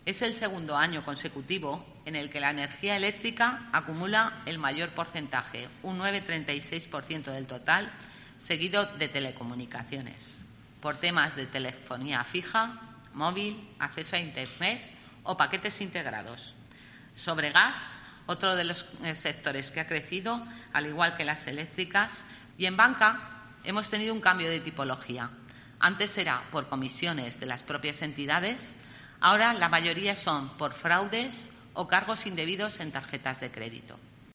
La directora general de Protección de Consumidores explica los motivos de las reclamaciones de 2023